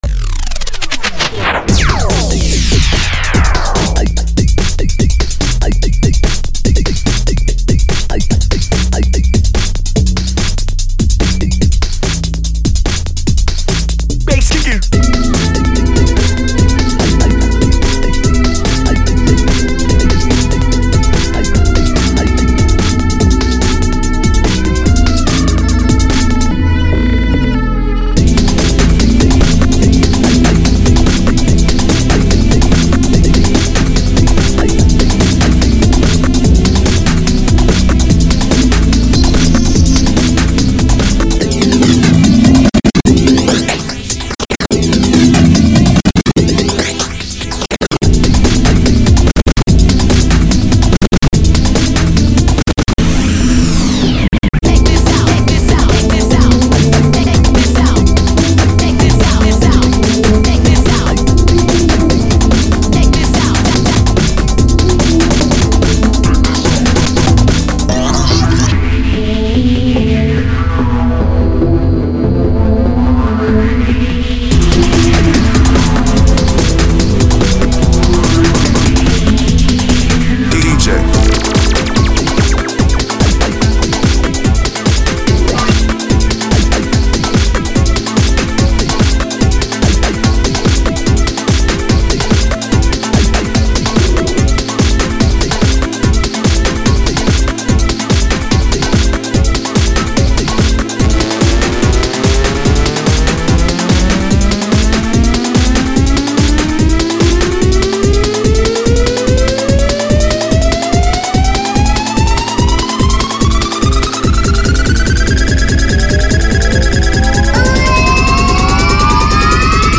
BGM
EDMロング